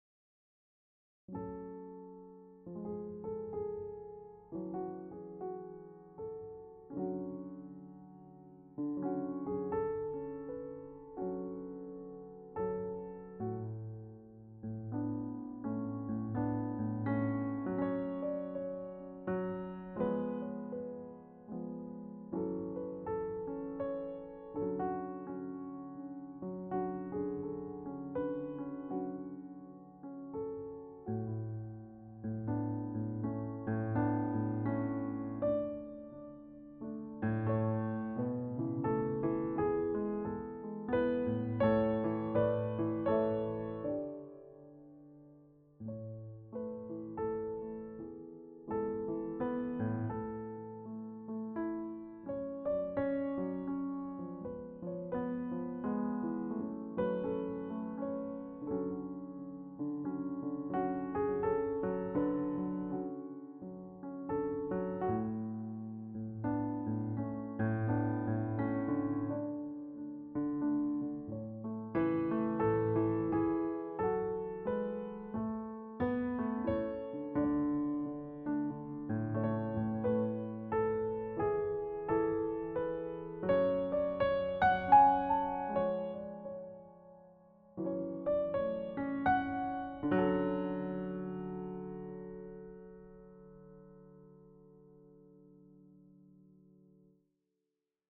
This is a short, solo piano version.
So relaxing!